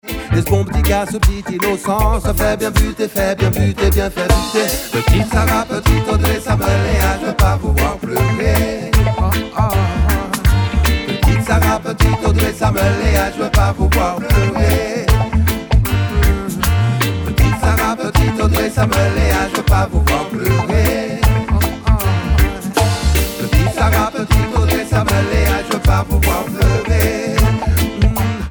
chant/lead
basse/bass
batterie/drum
clavier/keyboard
guitare/guitar
melodica
flûte/flute